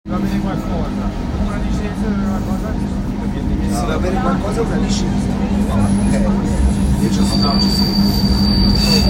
마테호른 산악기차.m4a
겨울 기차소리